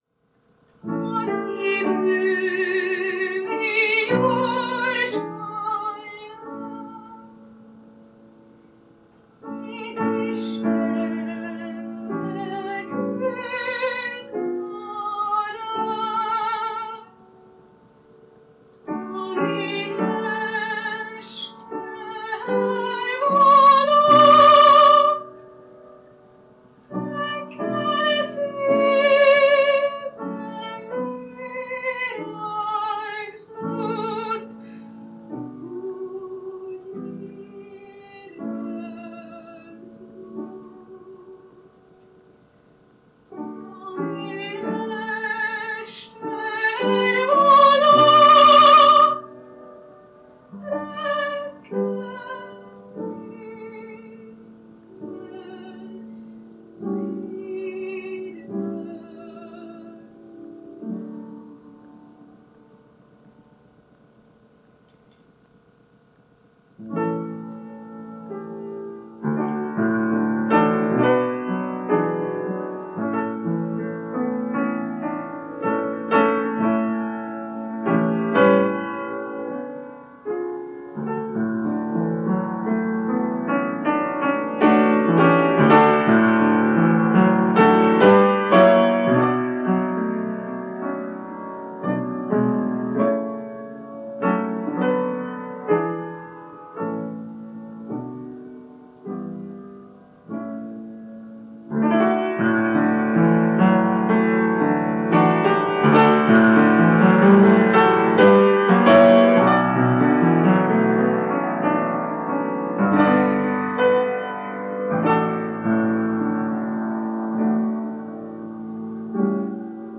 Ének
Zongora